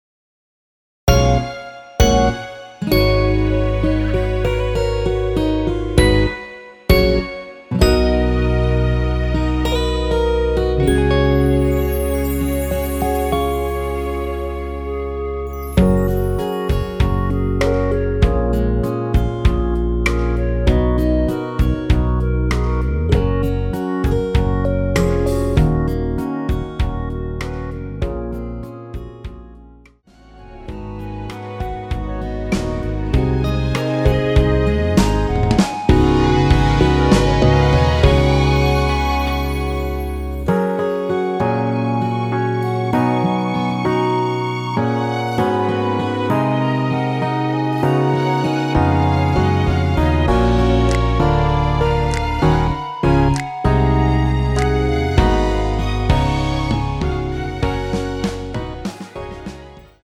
원키에서(+4)올린 1절후 후렴으로 진행되는 멜로디 포함된 MR입니다.
F#
노래방에서 노래를 부르실때 노래 부분에 가이드 멜로디가 따라 나와서
앞부분30초, 뒷부분30초씩 편집해서 올려 드리고 있습니다.
중간에 음이 끈어지고 다시 나오는 이유는